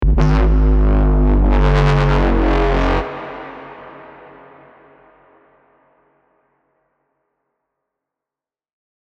Foghorn Bass 16 G# (FX)
Antidote_Zodiac-Forghorn-Bass-16-G-FX.mp3